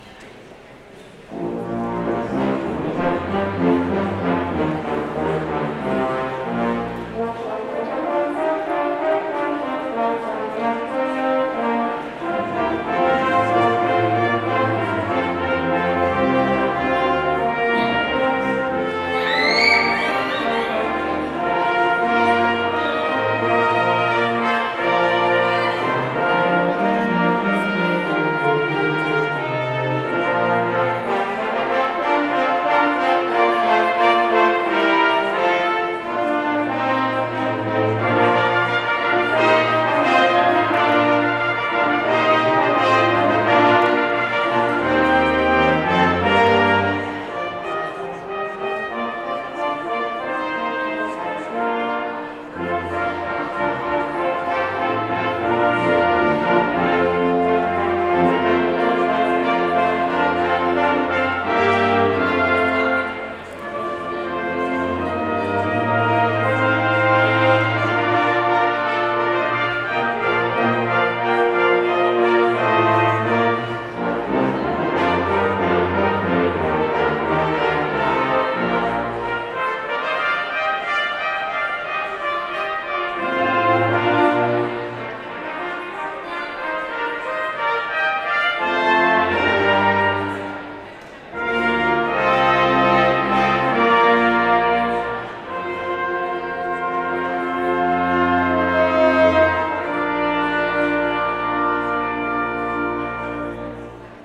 Concordia University Brass Ensemble
Christ Is Arisen The Strife Is O'er, the Battle Done Love Divine, All Loves Excelling Good Christian Friends, Rejoice and Sing Postlude - Brass - Hallelujah Amen - Mozart
Postlude-Brass-Hallelujah-Amen-Mozart.mp3